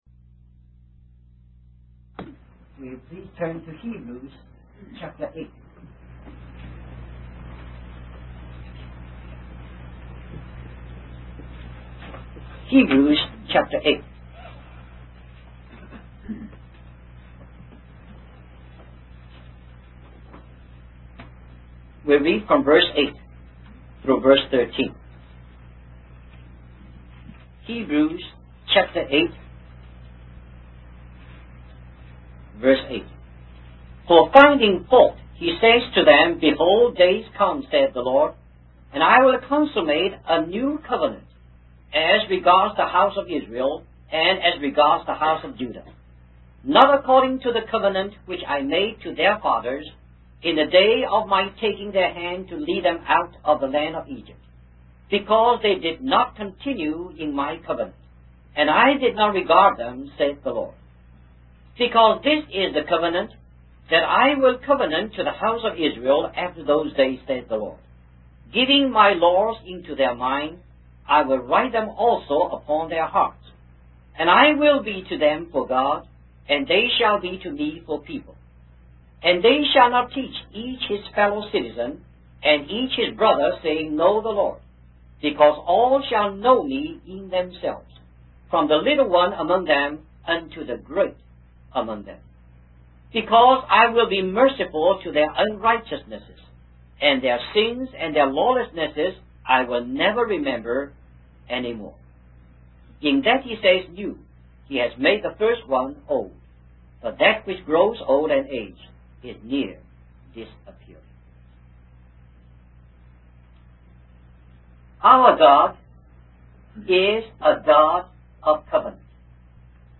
In this sermon, the preacher discusses the three main aspects of the New Covenant. The first aspect is that God will write His laws on the minds and hearts of His people.